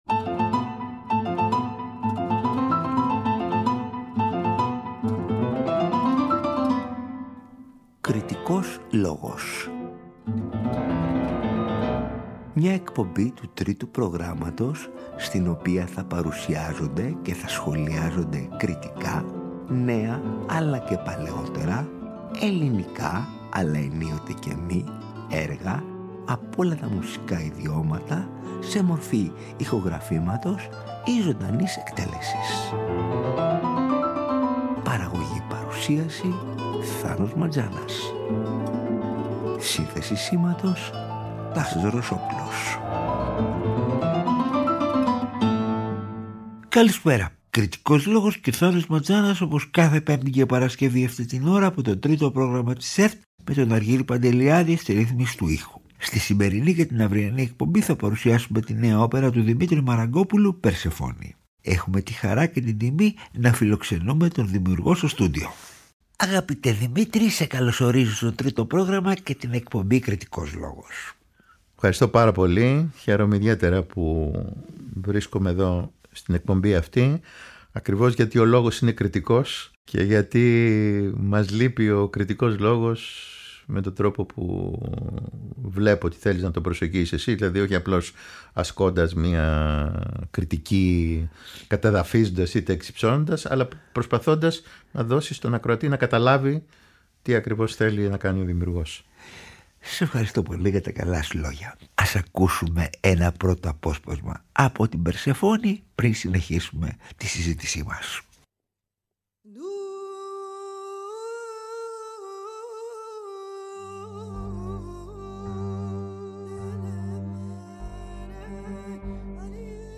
Ο Δημήτρης Μαραγκόπουλος παρουσιάζει τη νέα του ‘Οπερα «Περσεφόνη» (μέρος α’)
Καλεσμένος στο στούντιο του Τρίτου Προγράμματος ο ίδιος ο συνθέτης, στο πλαίσιο της εκπομπής «Κριτικός Λόγος» την Πέμπτη 7 και την Παρασκευή 8 Μαρτίου 2024, στις 18.00